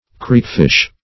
Creekfish \Creek"fish\ (kr?k"f?sh), n. (Zool.)
creekfish.mp3